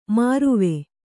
♪ māruve